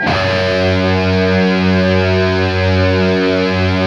LEAD F#1 LP.wav